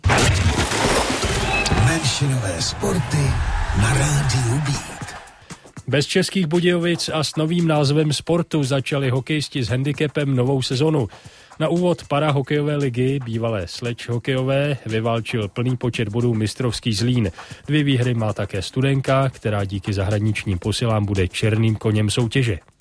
Reportáž z 1. a 2. kola ČPHL 2018/2019